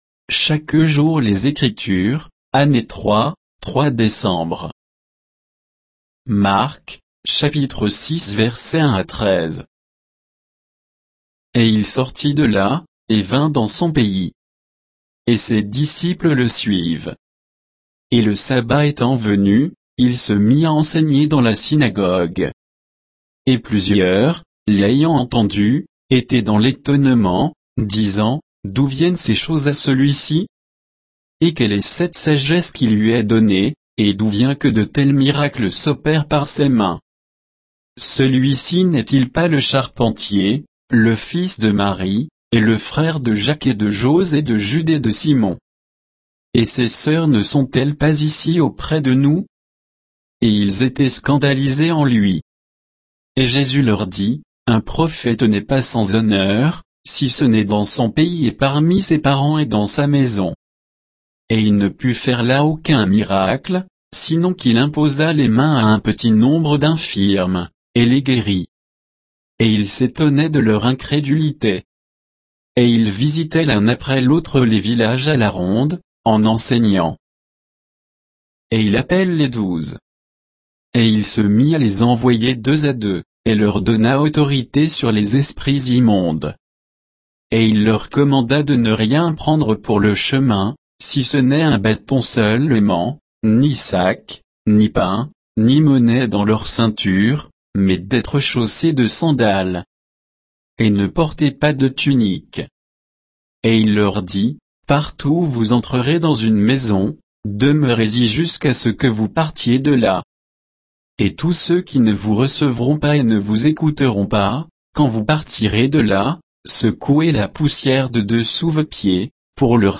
Méditation quoditienne de Chaque jour les Écritures sur Marc 6, 1 à 13